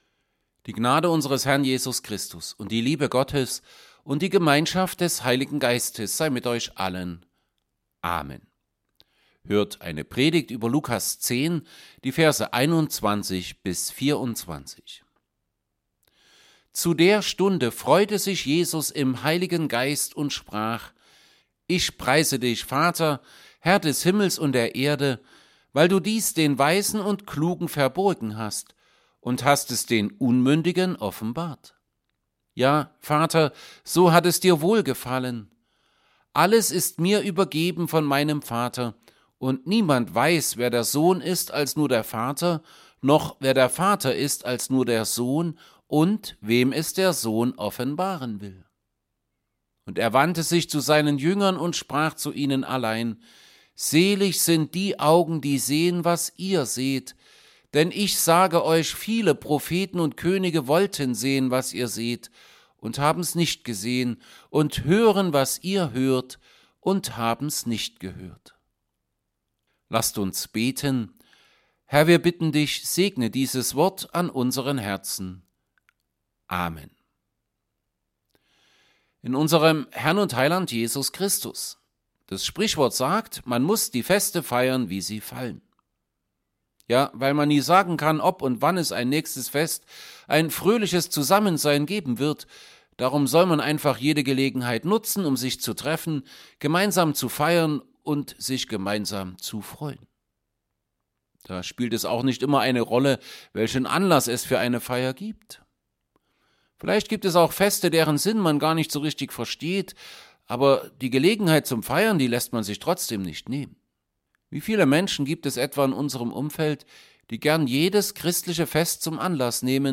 Serie: Evangelienpredigten Passage: Luke 10:21-24 Gottesdienst: Gottesdienst %todo_render% Dateien zum Herunterladen Notizen « Pfingsten 1.